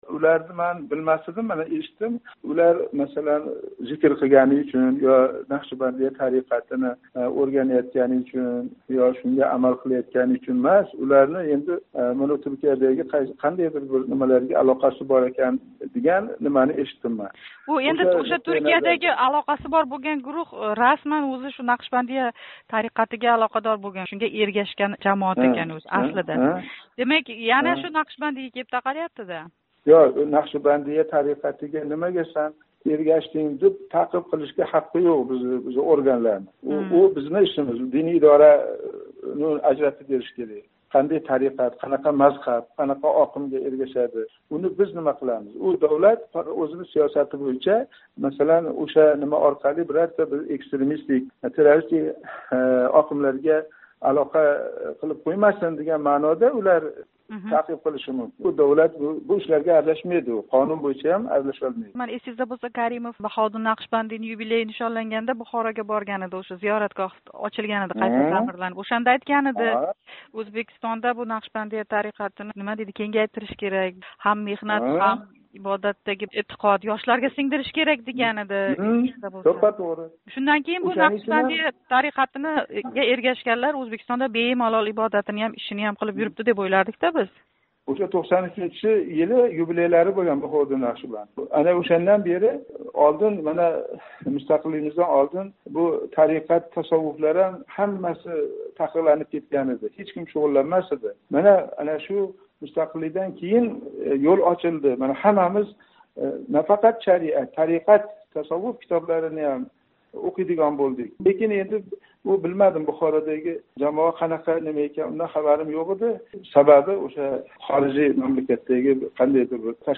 Бухородаги суд жараёни ва умуман, Ўзбекистонда Нақшбандийлик тариқатига эргашган диндорларга нисбатан ҳукумат сиёсати ҳақида Диний идора раиси ўринбосари Абдулазиз Мансур билан суҳбатни қуйида тўлиқ тинглашингиз мумкин: